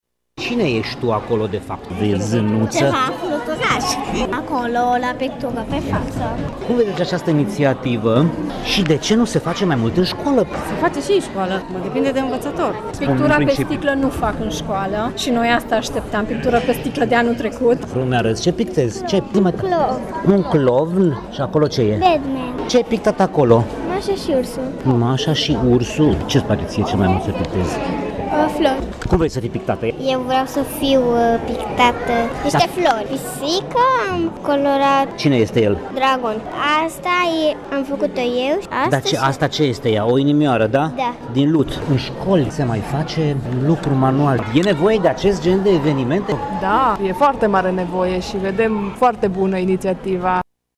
Organizatorii ne-au asigurat că s-au folosit vopsele speciale pentru piele, fără risc pentru sănătate: